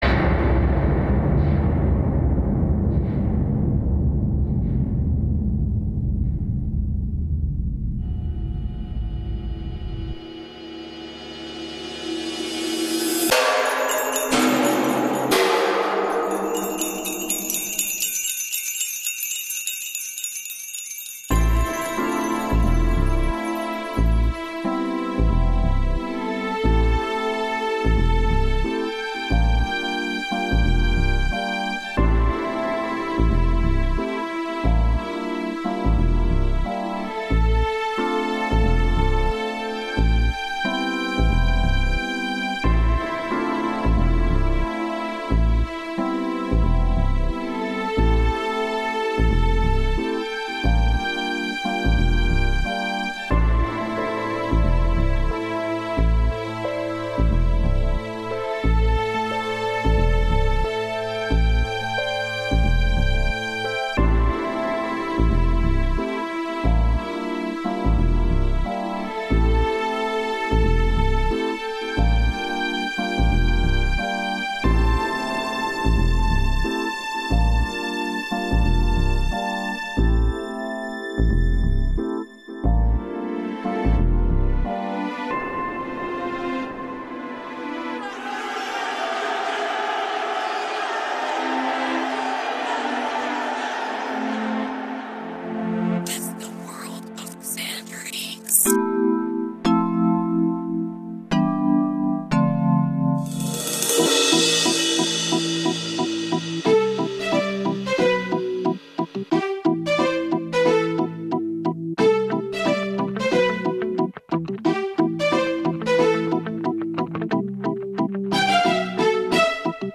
Музыкальные композиции в готическом стиле
Такая загадочная атмосфера.